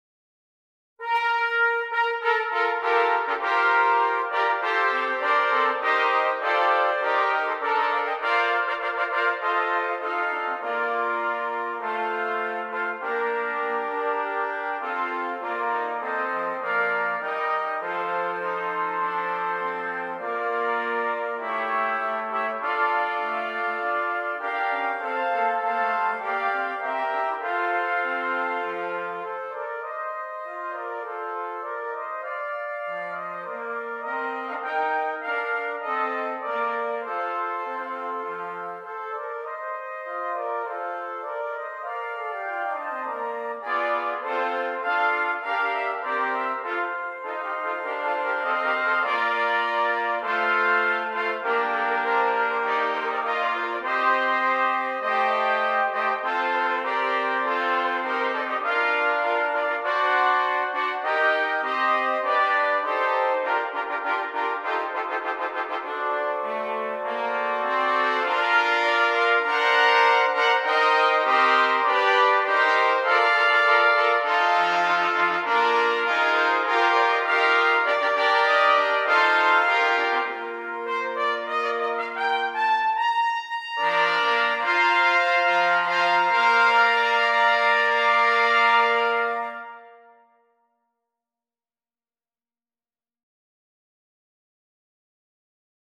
5 Trumpets